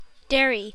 Ääntäminen
UK : IPA : /ˈdɛəɹi/